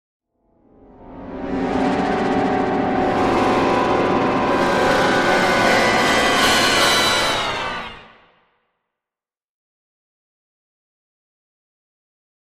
Brass Section, Accent, "The Bad End", Type 2 - Heavy Version